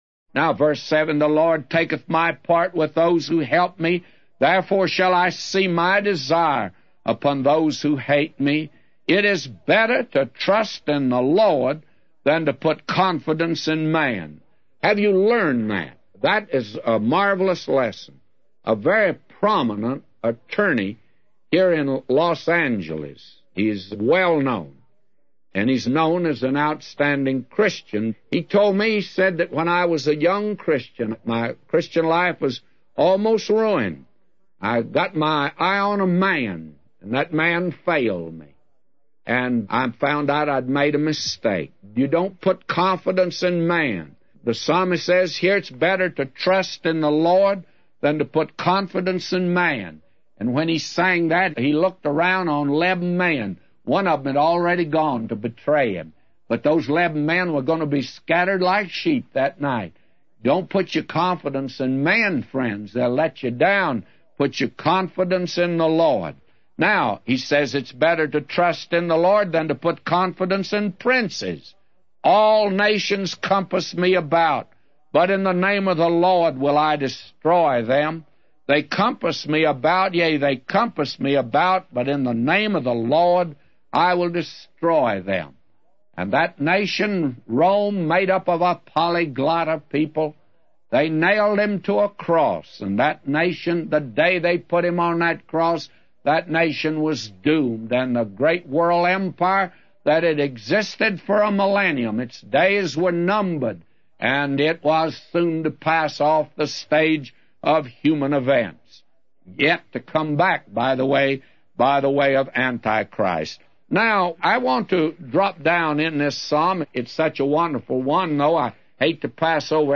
A Commentary By J Vernon MCgee For Psalms 118:7-999